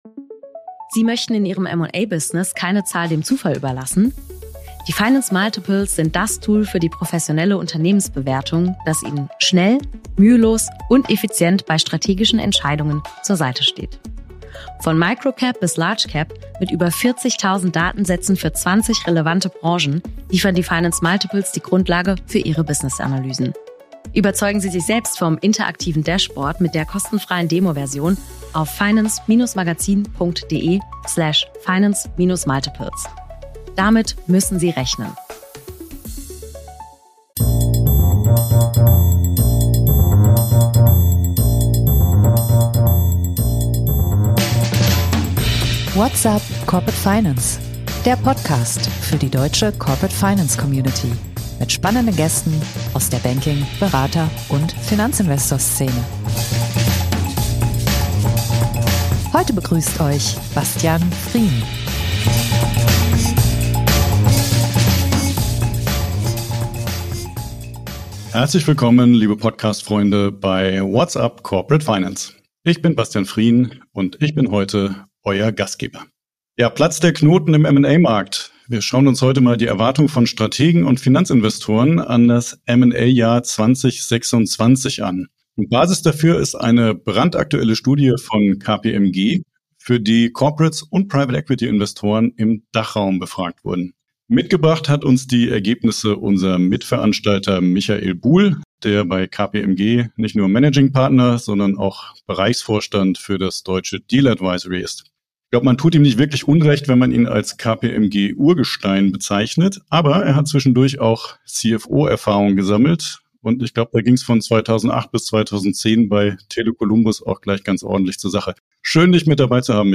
Ein Highlight war das M&A-Jahr 2025 nicht, aber wie blicken Strategen und Finanzinvestoren auf 2026? Eine Studie von KPMG bringt interessante Erkenntnisse – und in einer hochkarätigen Runde diskutieren wir nicht nur die Kernaussagen, sondern weitere spannende Aspekte eines M&A-Markts im Umbruch.